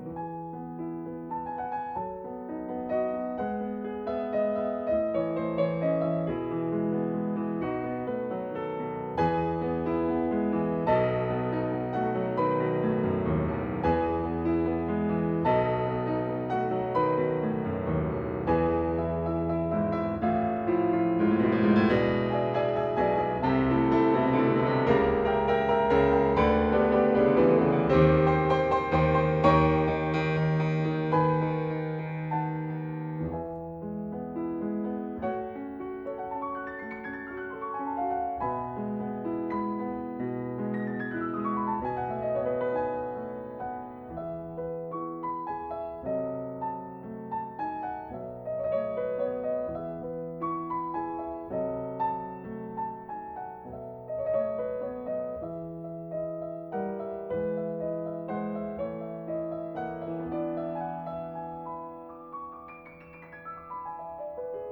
更不要提這個音樂多麼好聽，會讓我聯想到蕭邦和莫扎特，但是又那麼不同。